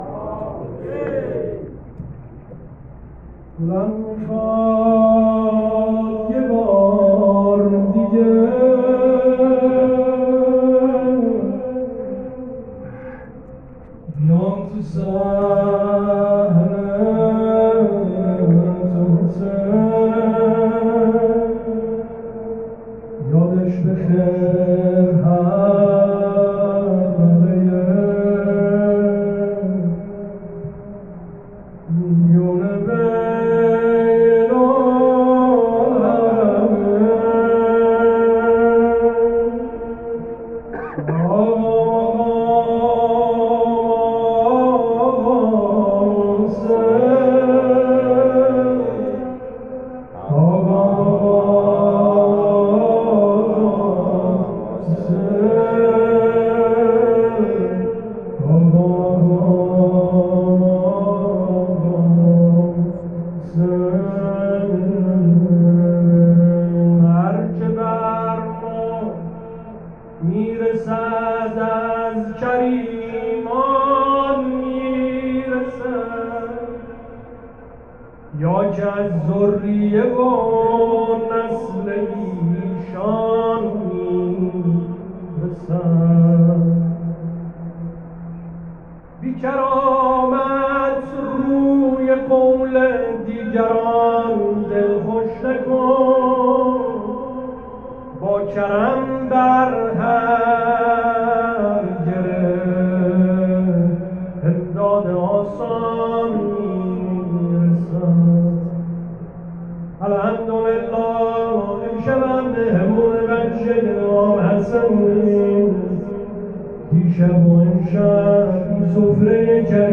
مداحی
شب ششم محرم ۱۴۰۱